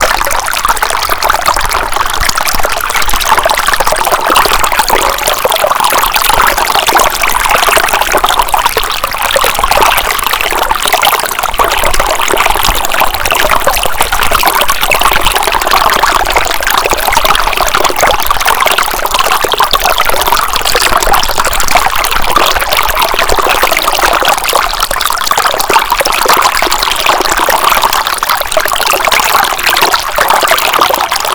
Sounds of the Escondido Creek Watershed
Escondido Creek Waterfall
Escondido Creek waterfall (2).wav